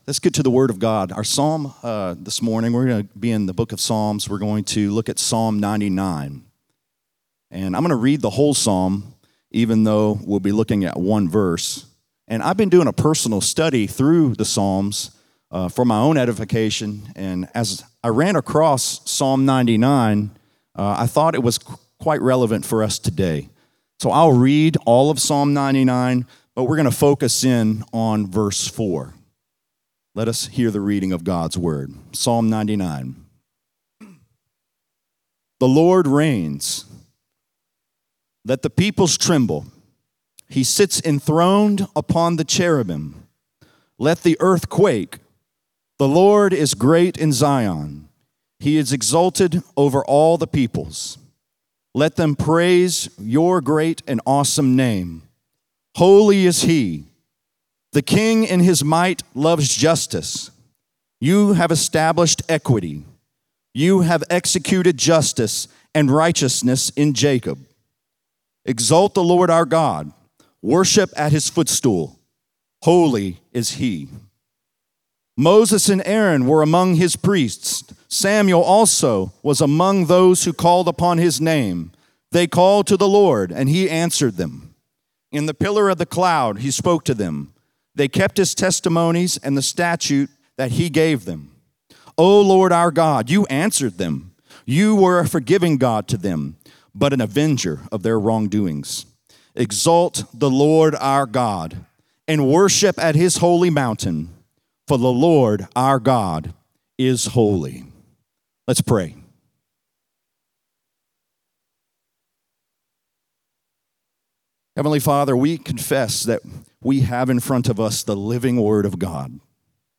Equity | Lafayette - Sermon (Psalm 99)